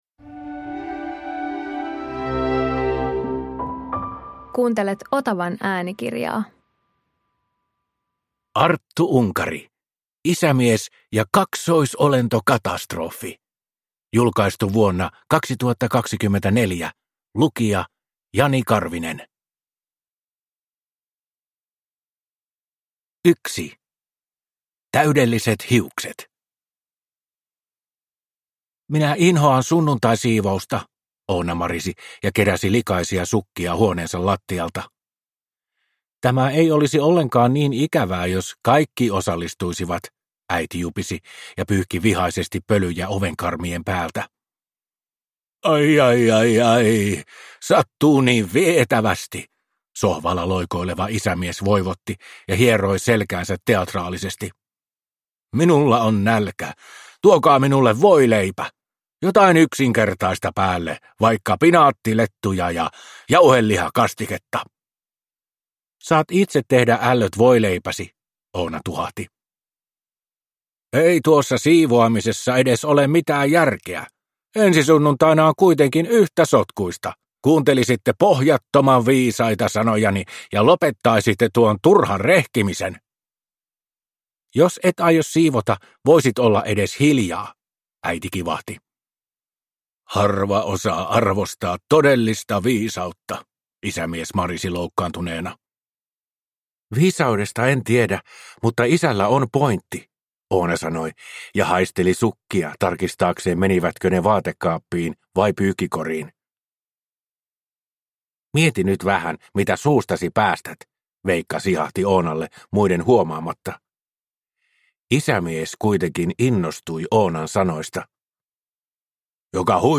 Isämies ja kaksoisolentokatastrofi – Ljudbok